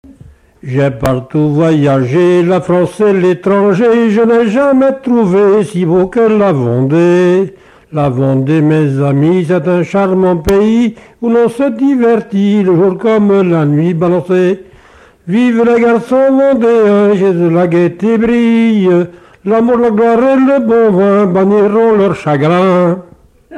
Figure du quadrille vendéen
Chants brefs - A danser
Pièce musicale inédite